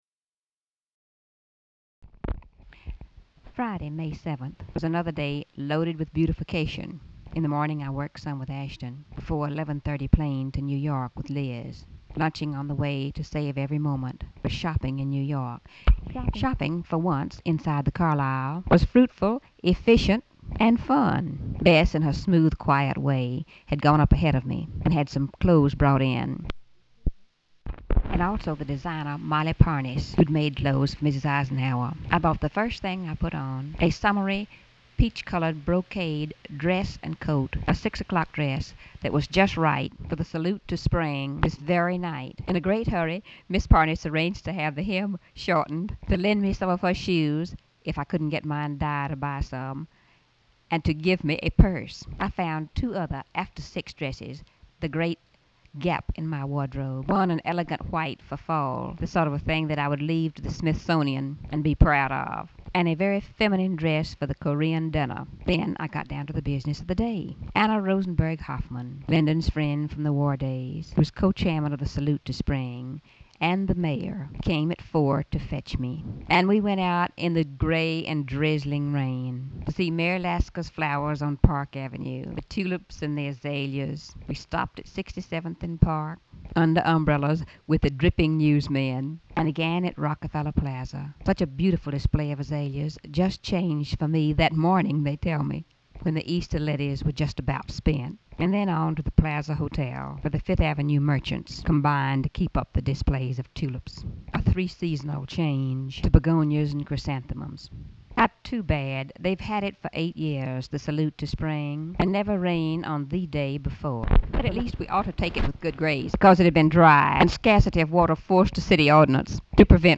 Audio diary and annotated transcript, Lady Bird Johnson, 5/7/1965 (Friday) | Discover LBJ